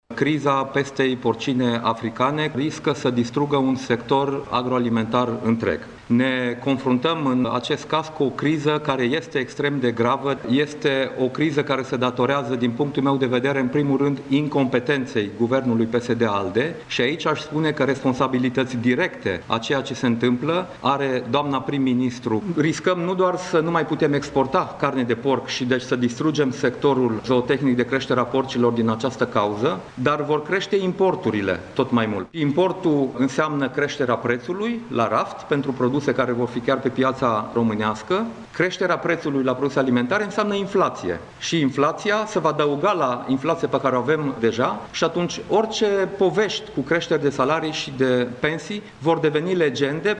Exportul de carne de porc din România ar putea fi sistat, pentru o perioadă, din cauza pestei porcine africane, avertizează Dacian Cioloş – fost premier, ministru al Agriculturii şi comisar european de resort. El a vorbit, într-o conferinţă de presă, şi despre alte consecinţe ale acestei crize.